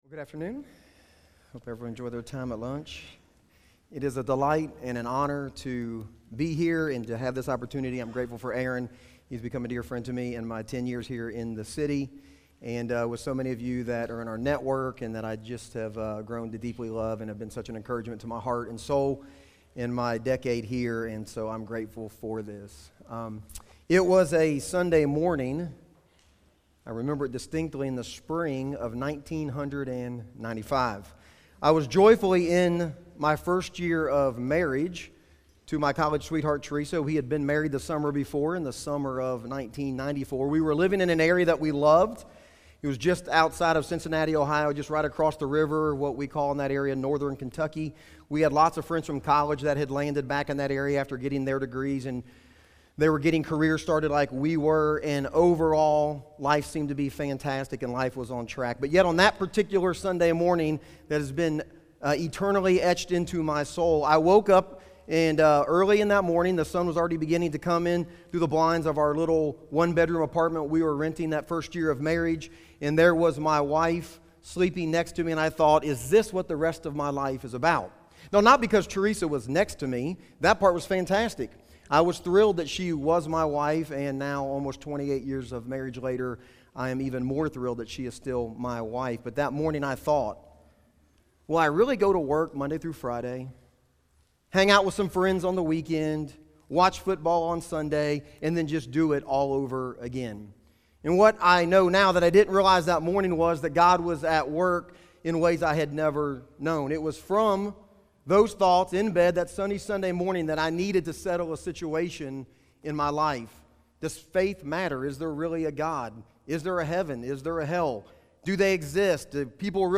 Audio recorded at Feed My Sheep Conference 2022.